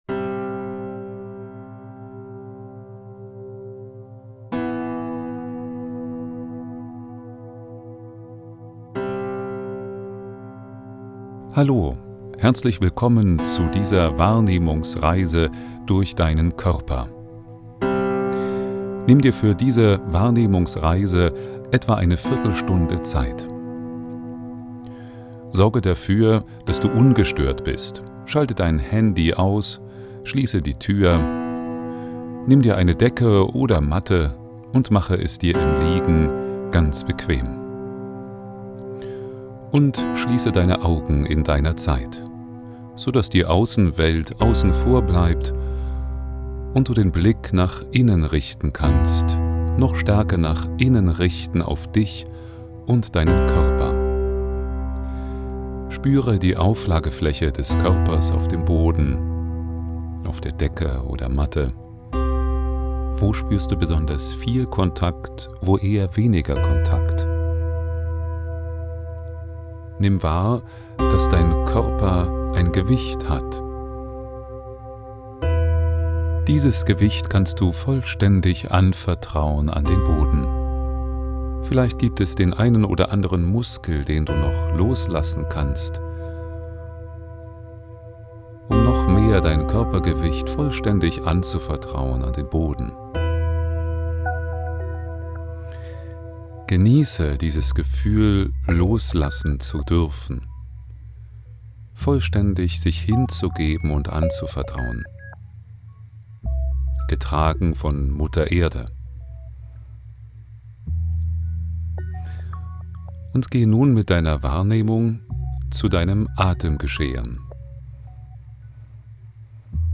Live-Online-Meditation
Geführte Wahrnehmungsreise Ausschnitt.mp3